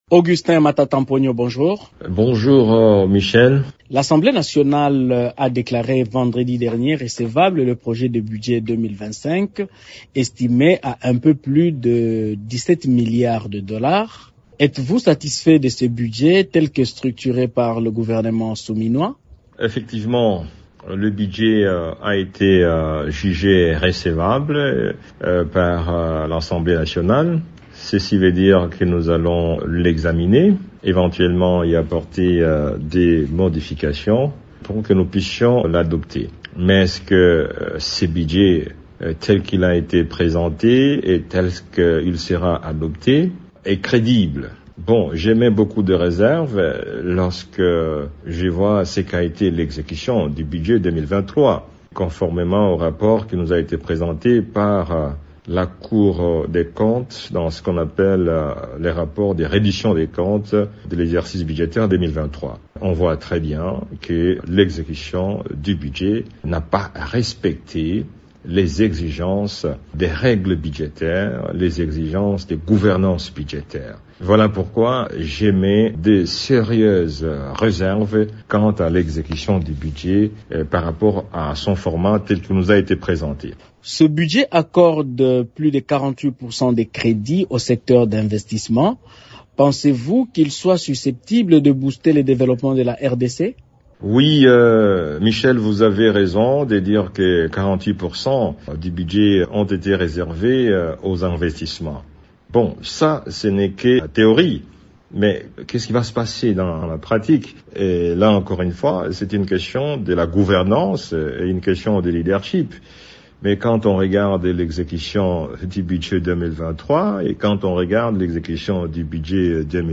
Invité de Radio Okapi ce lundi 4 novembre, cet élu s’exprime quelques jours après que le projet de loi des finances 2025 a été jugée recevable à l’Assemblée nationale.